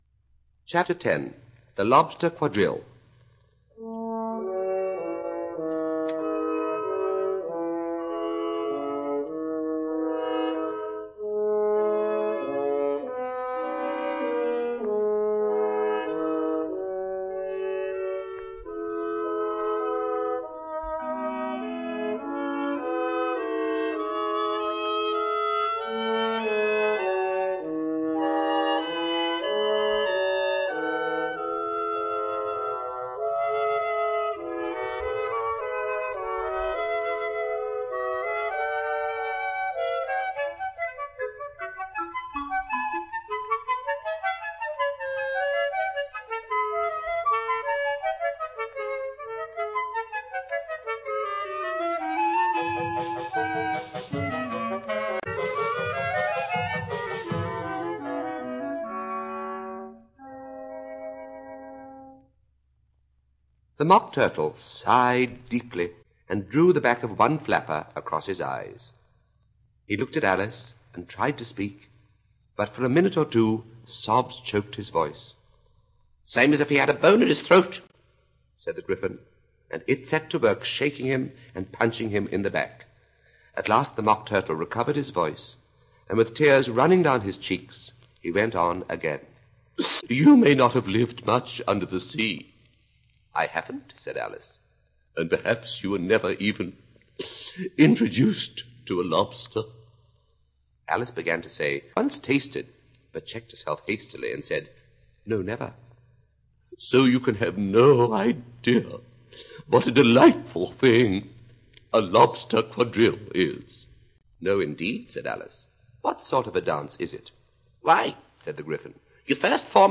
Kids Learning Centre - Alice In Wonderland by Lewis Carroll, Read and Sung by Cyril Ritchard.